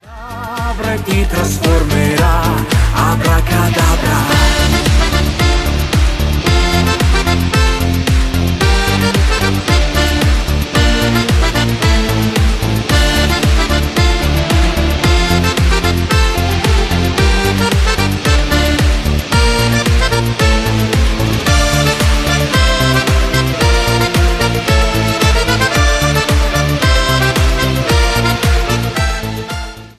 CUMBIA  (03.44)